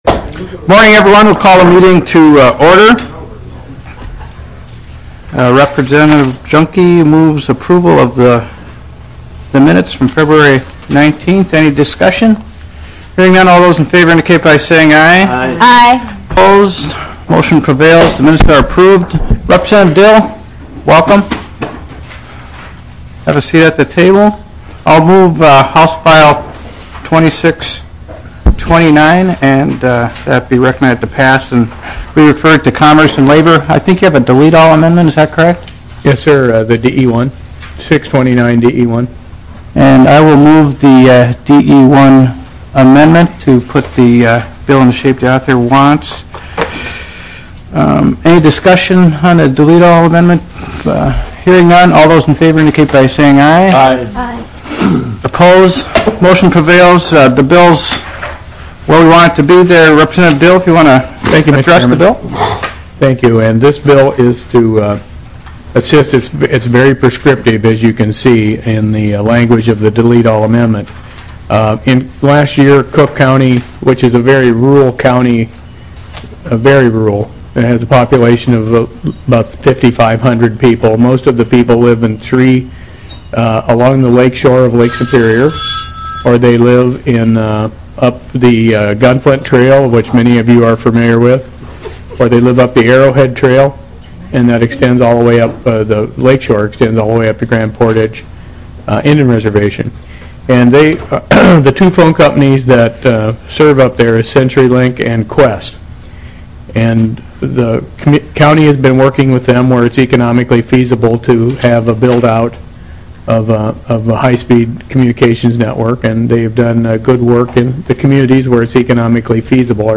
Telecommunications Regulation and Infrastructure Division 5/5/2010 Audio Available: Download Mp3 Meeting Details - Wednesday, May 5, 2010, 10:00 AM Show Agenda Text This is an informational hearing only, no official action will be taken. - Discussion of intrastate access fees - Broadband mapping update